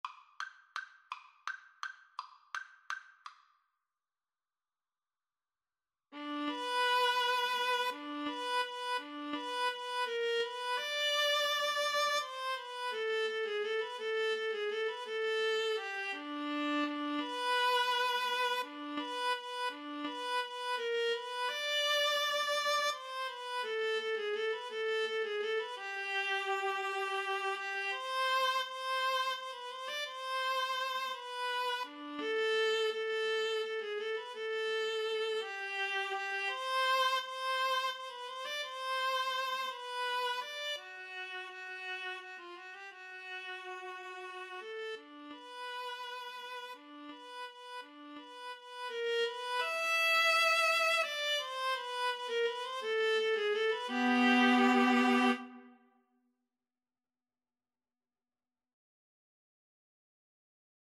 Tempo di valse =168
3/4 (View more 3/4 Music)
Viola Duet  (View more Easy Viola Duet Music)
Classical (View more Classical Viola Duet Music)